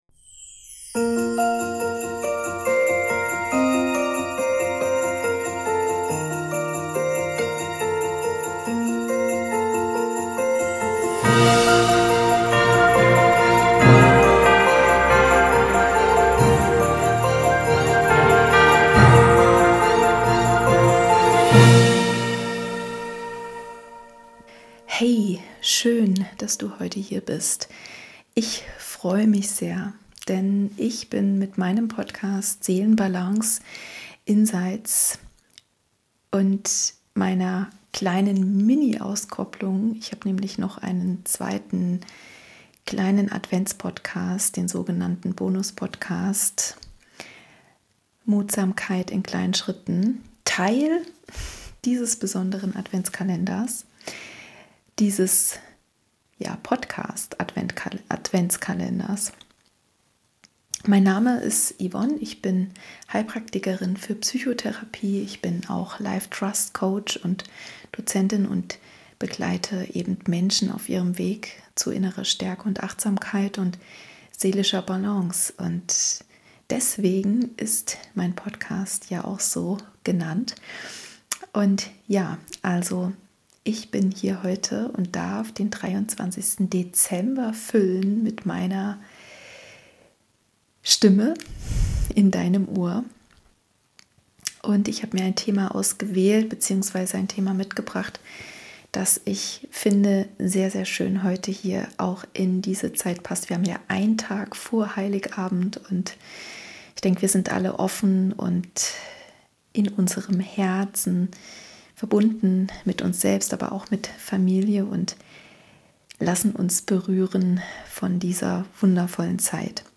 In dieser warmen Adventsfolge begleite ich dich in einen Moment Dankbarkeit. Du hörst eine kleine Geschichte, die daran erinnert, wie oft wir an Dingen vorbeigehen, die uns eigentlich Kraft schenken könnten.
Mit einer sanften Atemsequenz findest du Zugang zu dem, was in deinem Herzen bereits da ist. Du gönnst dir einen Moment Stille und öffnest einen Raum für innere Weite und Verbundenheit.